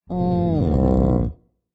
Minecraft Version Minecraft Version 1.21.5 Latest Release | Latest Snapshot 1.21.5 / assets / minecraft / sounds / mob / sniffer / idle11.ogg Compare With Compare With Latest Release | Latest Snapshot